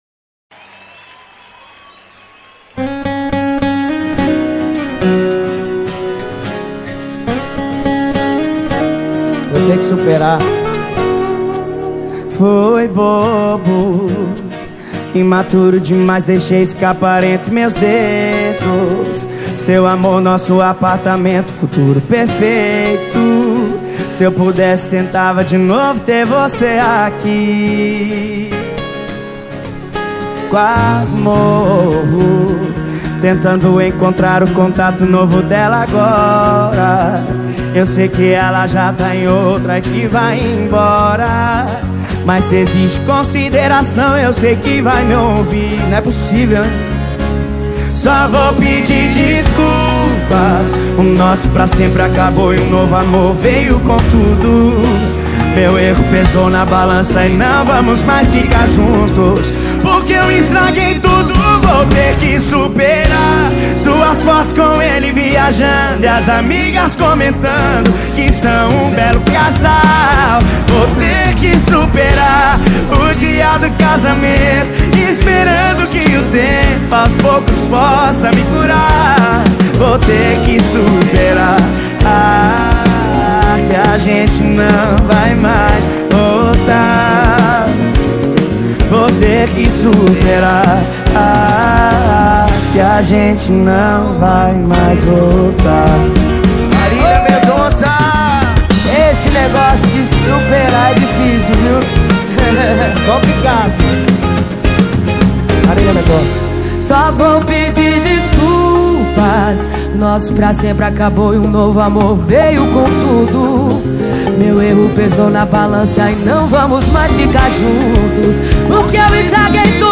2024-12-19 11:51:12 Gênero: Sertanejo Views